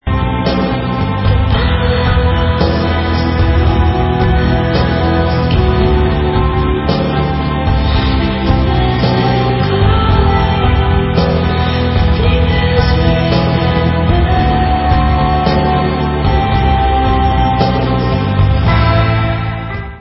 POP WAVE